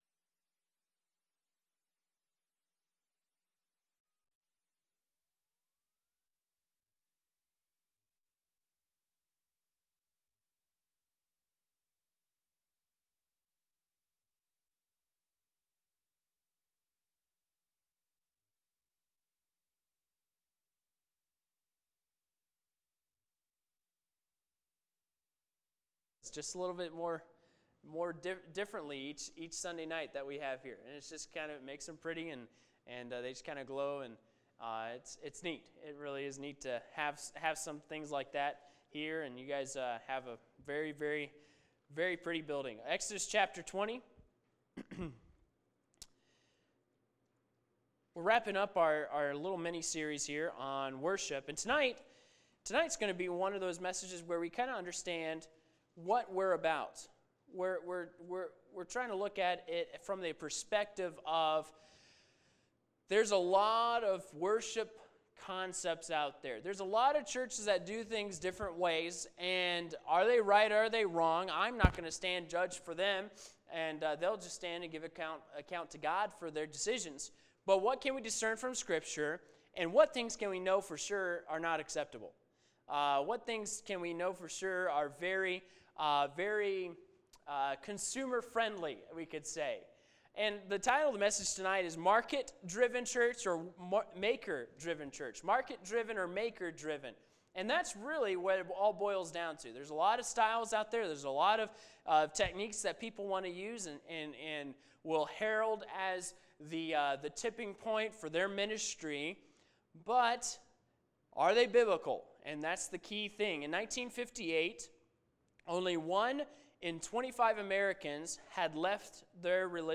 Sermons | Anthony Baptist Church